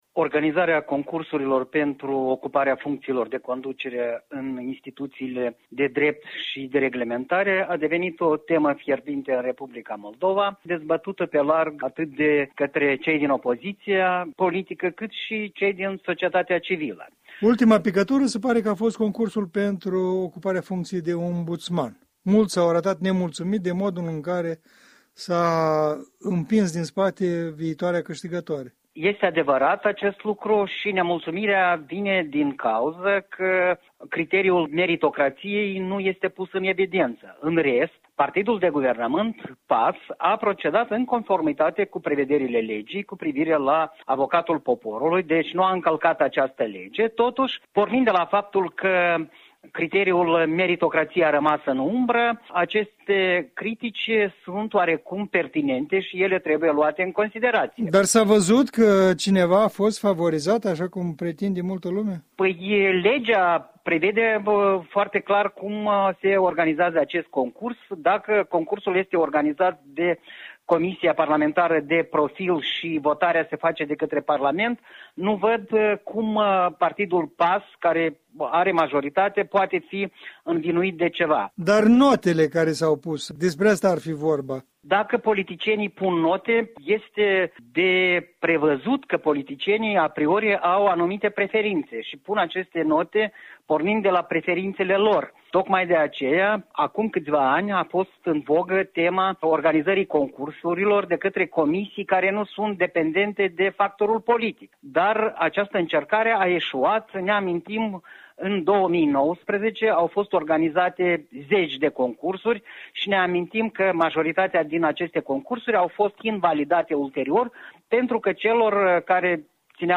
în dialog cu analistul politic